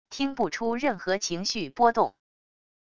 听不出任何情绪波动wav音频